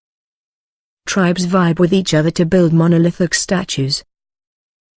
You will hear a sentence.